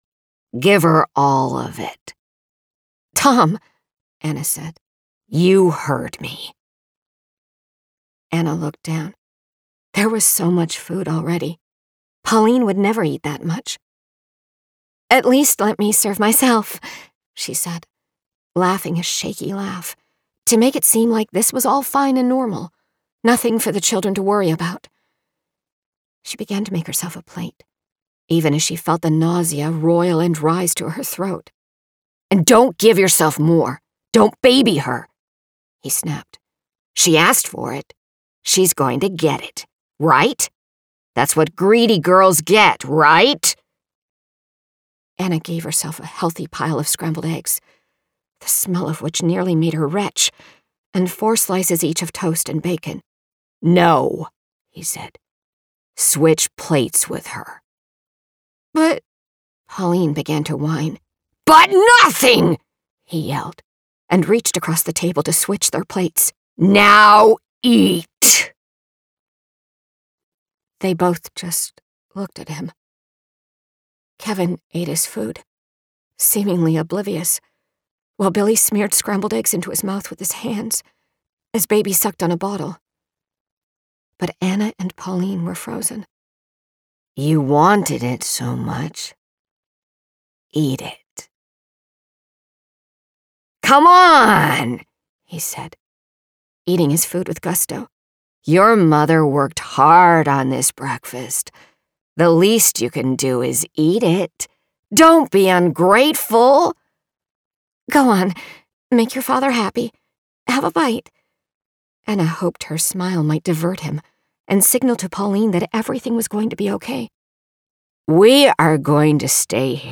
AUDIOBOOK  SAMPLES
Literary Fiction 1950s       |    Third Person    |  Male / Female Dialogue